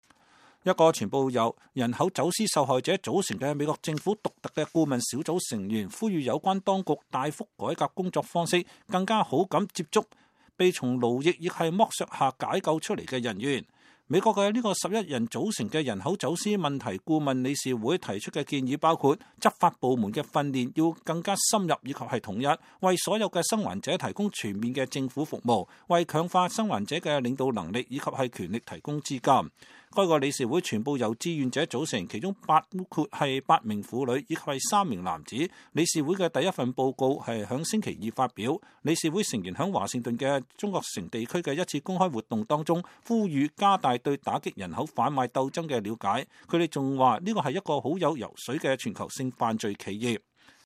11名成員組成的美國人口販運問題顧問理事會星期二發布第一份報告。理事會三名成員在討論他們的報告